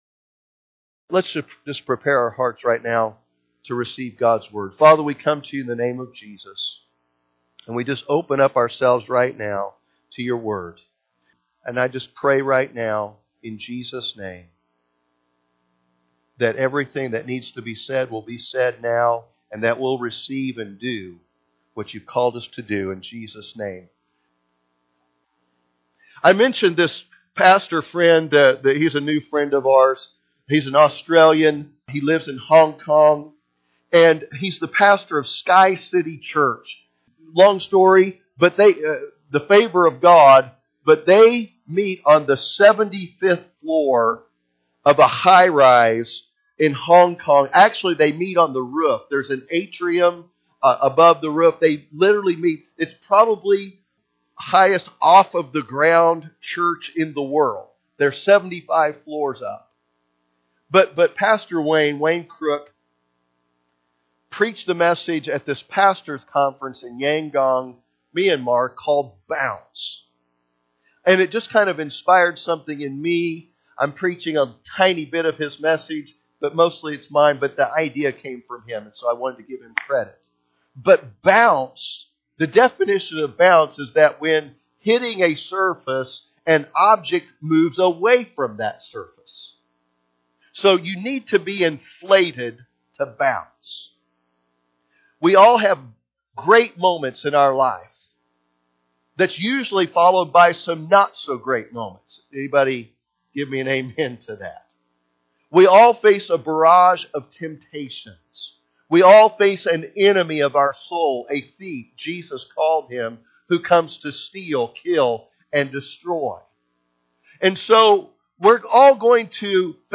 Sunday Morning Service
Sermon